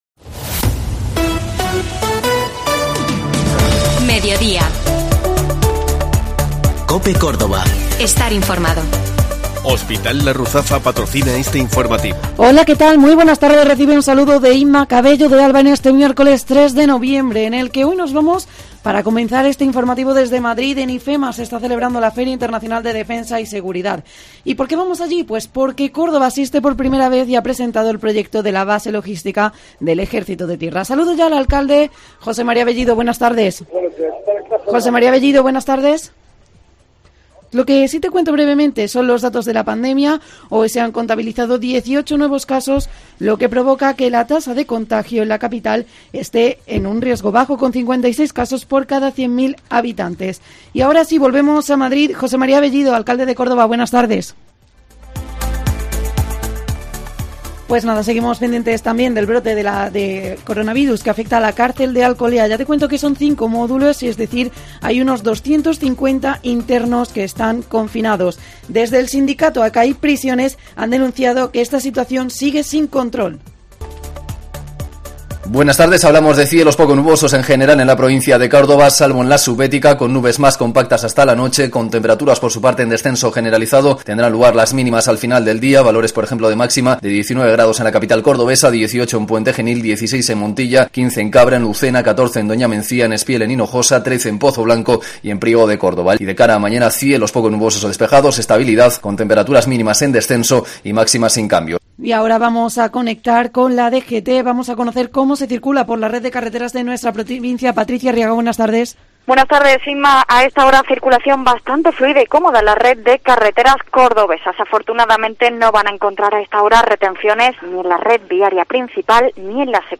Allí nos ha atendido el alcalde, José Mª Bellido, quien ha explicado cómo ha sido la presentación del proyecto de la Base Logística y lo que supone asistir a esta feria. Además, hablamos de los datos del paro y de la covid, así como de otras noticias de interés para Córdoba y su provincia.